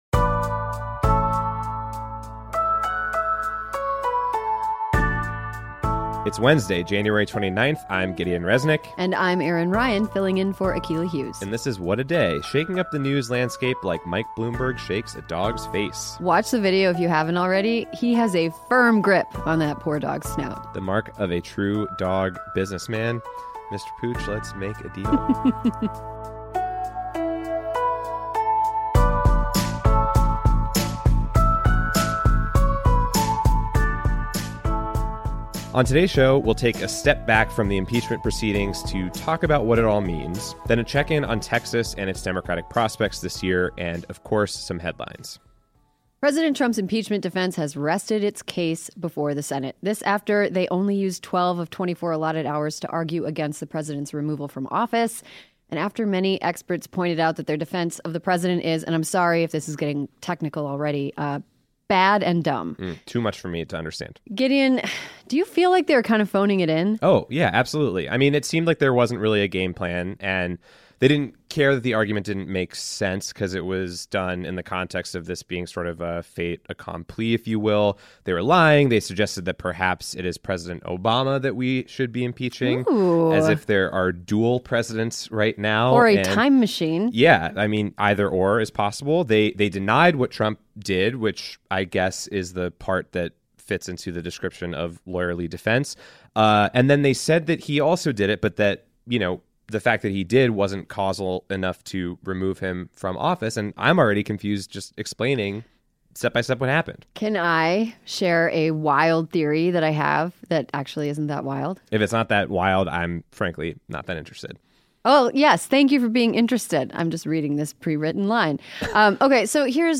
We interview former congressman Beto O'Rourke to find out what Texas dems are